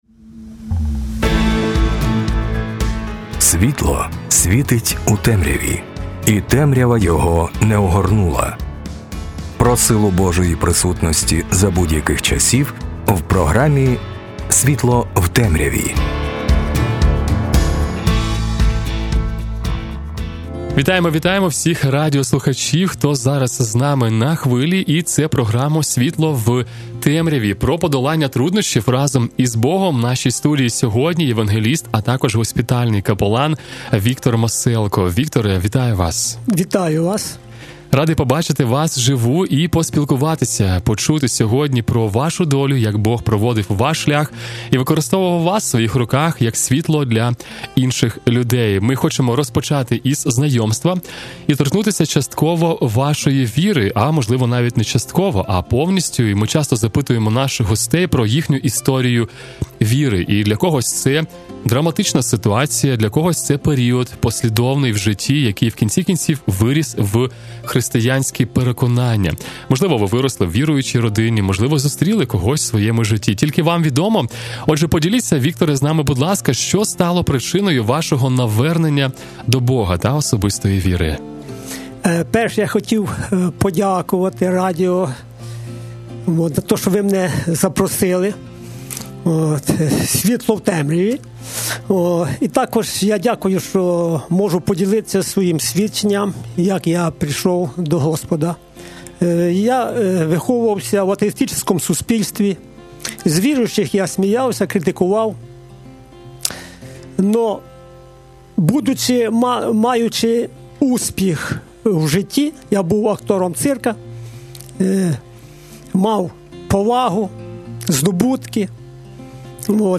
інтерв'ю